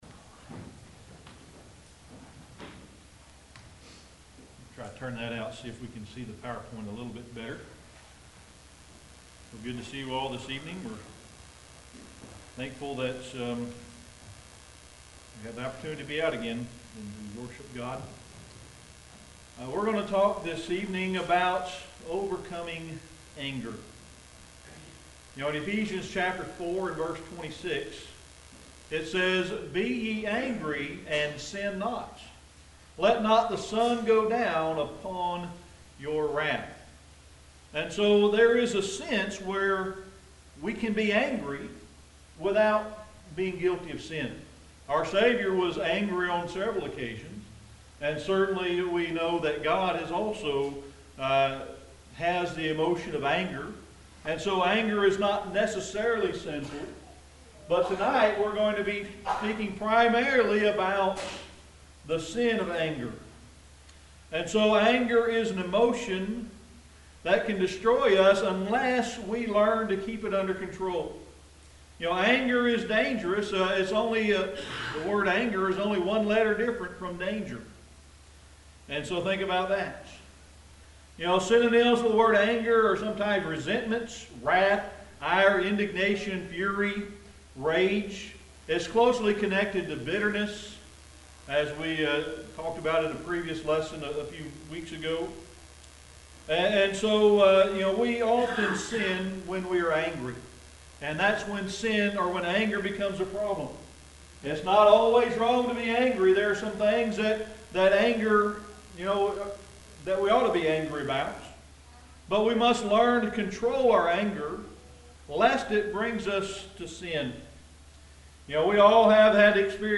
Sermon: Overcoming Anger